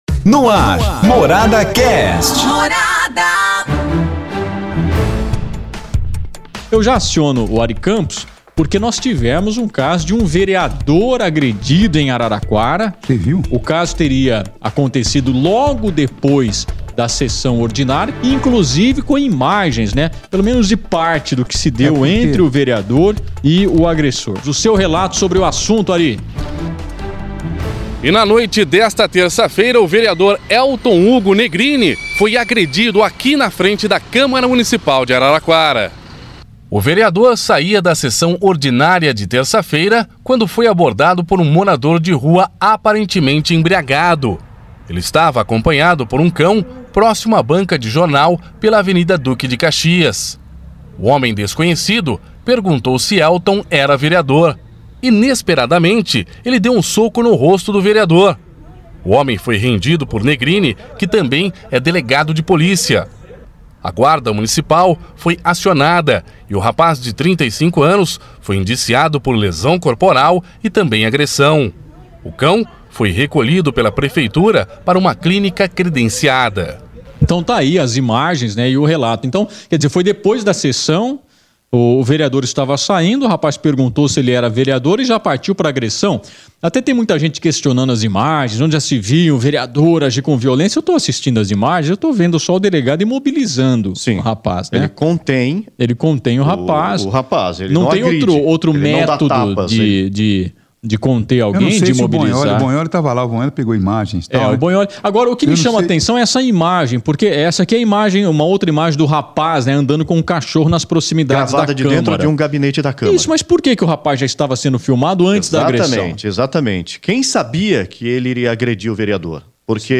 Nesse podcast os jornalistas comentam sobre o caso do vereador que foi agredido em Araraquara.